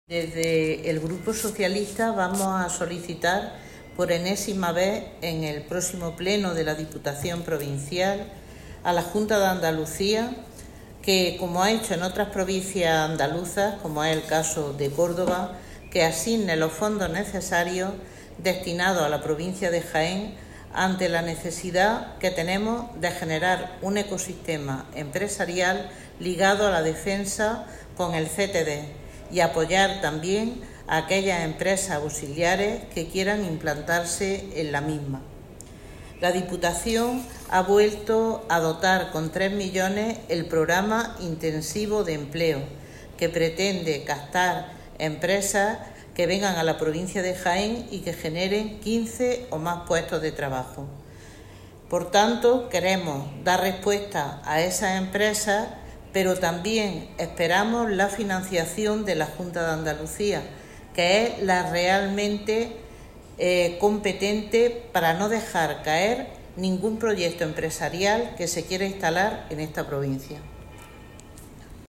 Cortes de sonido # Pilar Parra